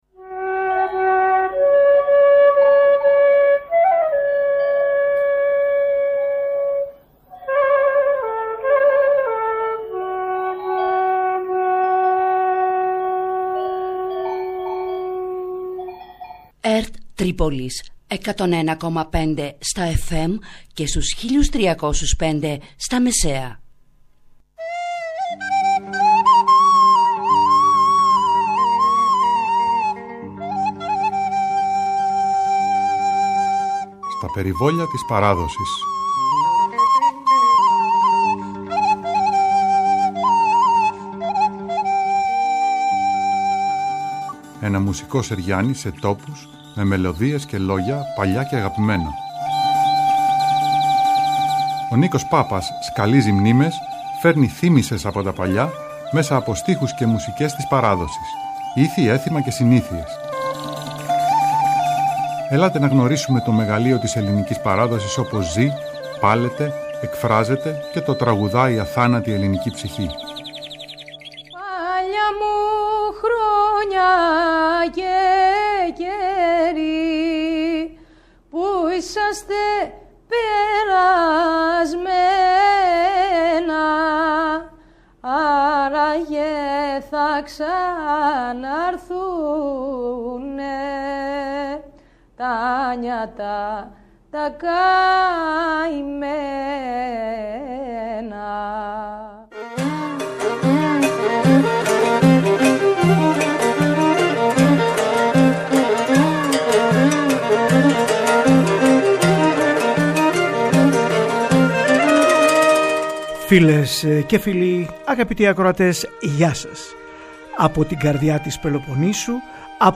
ΔΗΜΟΤΙΚΑ ΤΡΑΓΟΥΔΙΑ